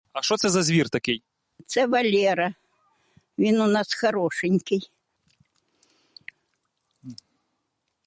Джек рассел терьер (англ. Jack Russell Terrier) sound effects free download